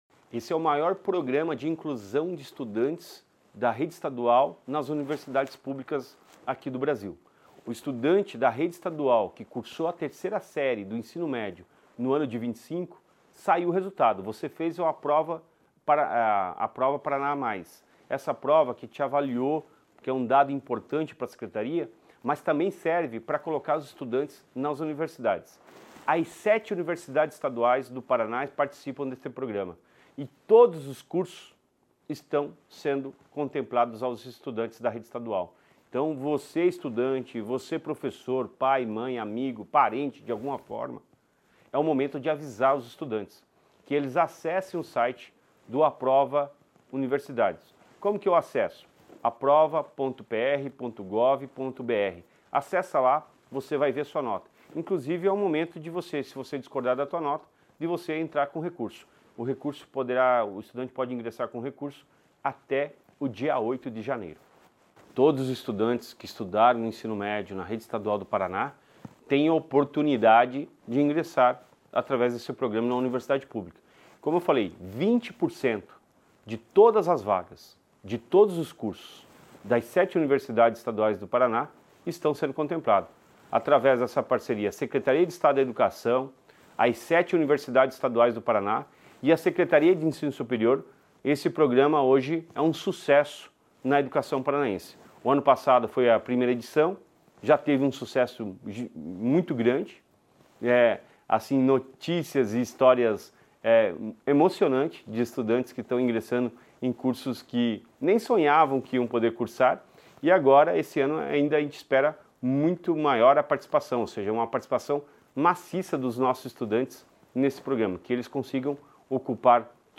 Sonora do secretário da Educação, Roni Miranda, sobre a Prova Paraná Mais